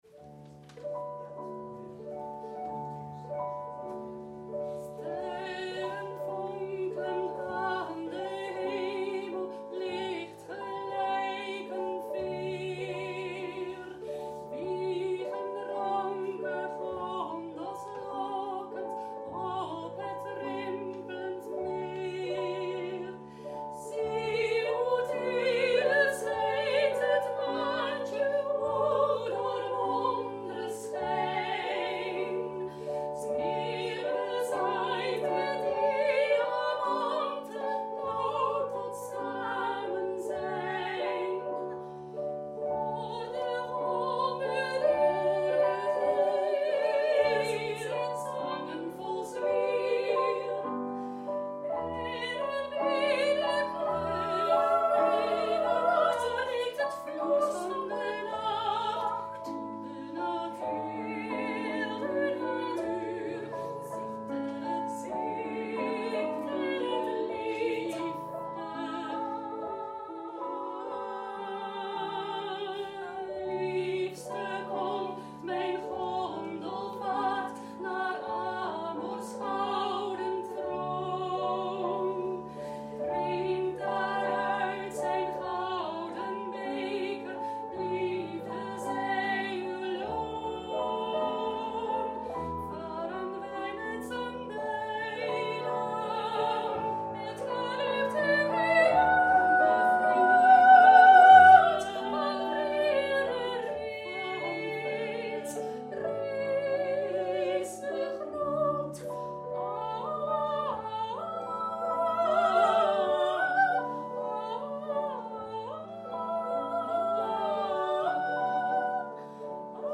BEGELEIDING LIED EN OPERA/ OPERETTE ARIA’S